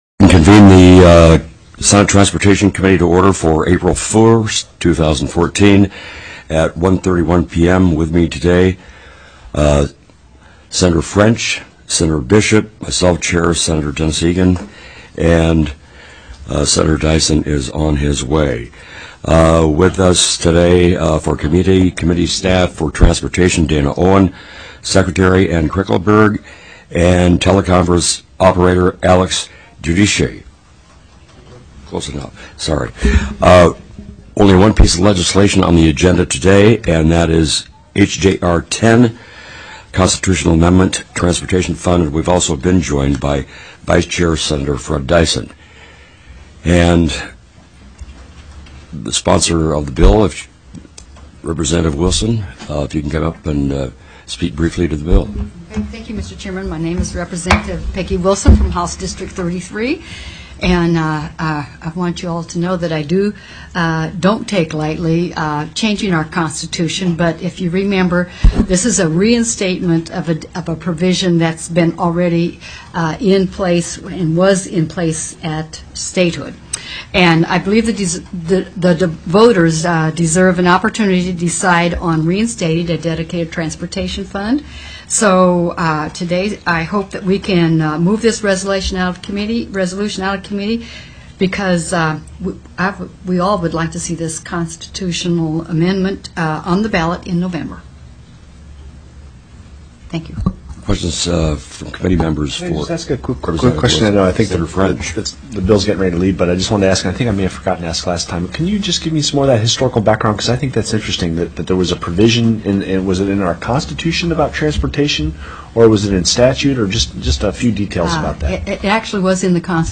04/01/2014 01:30 PM Senate TRANSPORTATION
+ Bills Previously Heard/Scheduled TELECONFERENCED